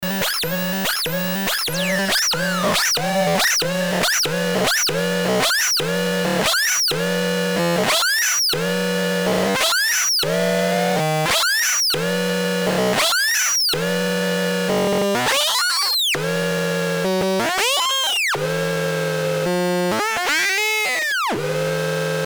- mono output
edit VOICE this noisebox uses a IC 40106 hex schmitt trigger and it generates 6 identical oscillators with individual pitch frequencies and a non periodic modulator LFO. It works like a source of random noises quite unpredictable but soon boring.